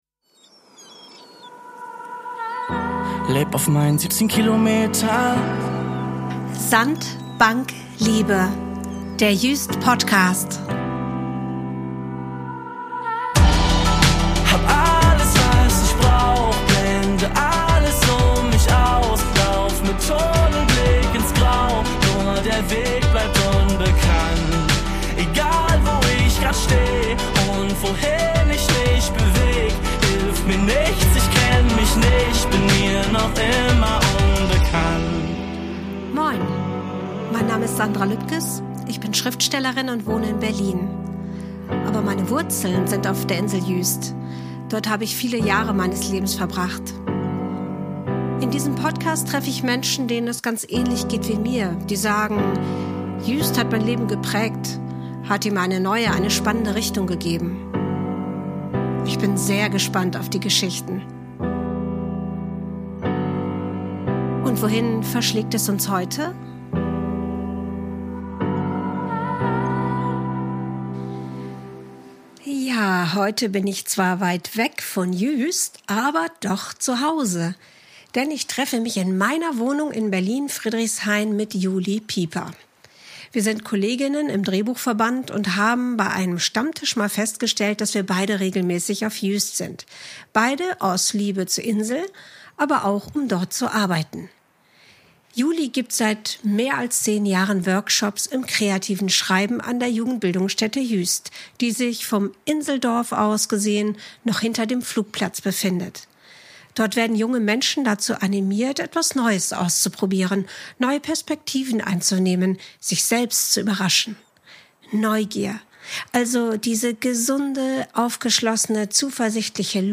in ihrer Wohnung in Berlin Friedrichshain